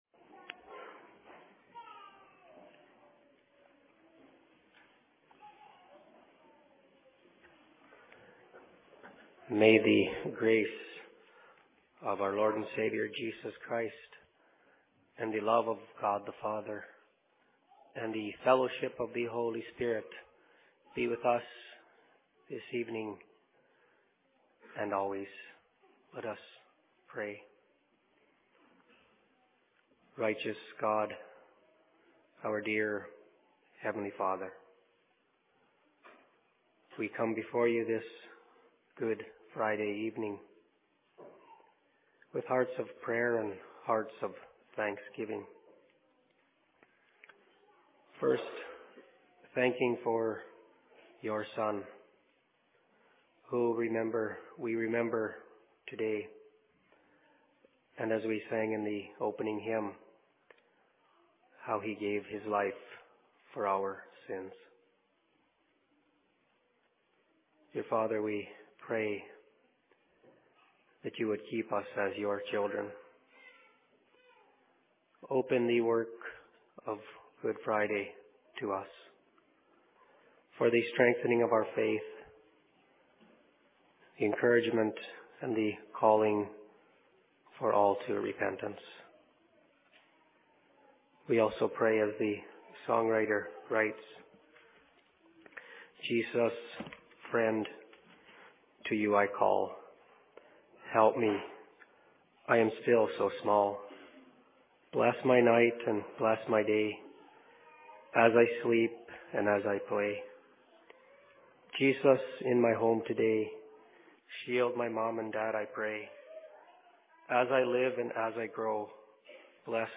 A Good Friday Services/Sermon in Longview 22.04.2011
Location: LLC Longview